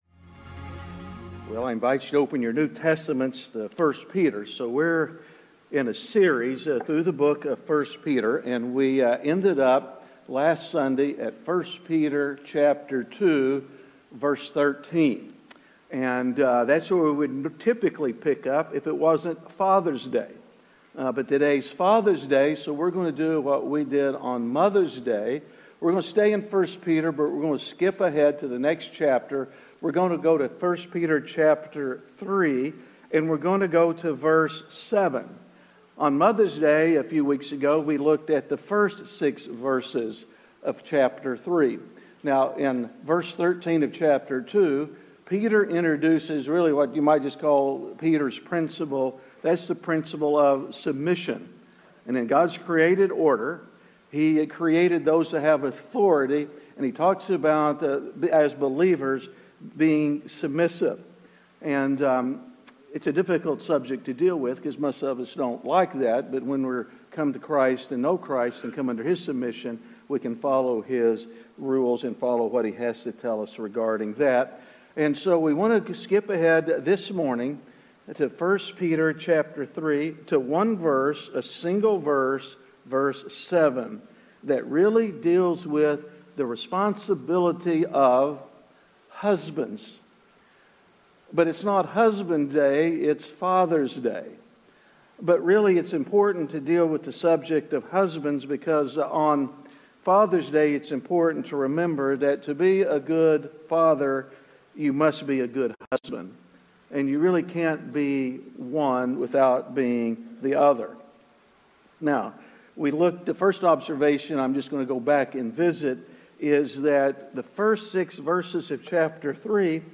Missed a Sunday? You can go back and catch up on any of the sermons you missed.